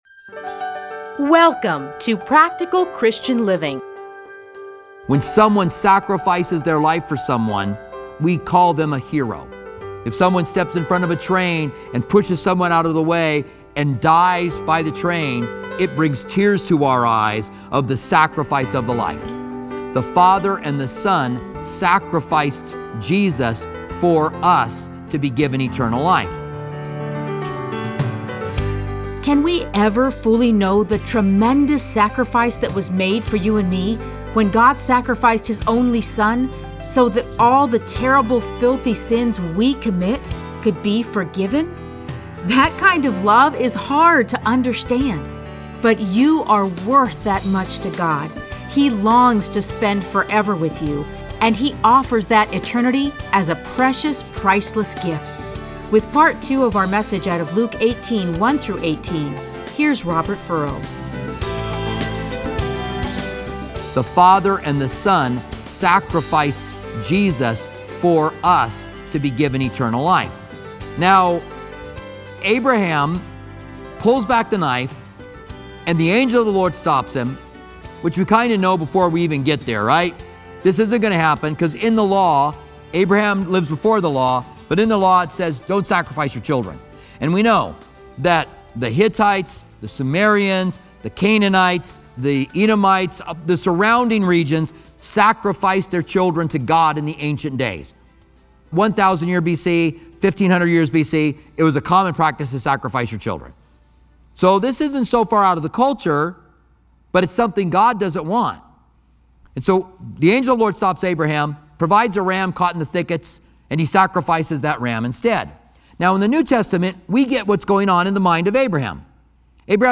Listen to a teaching from Luke 18:1-8.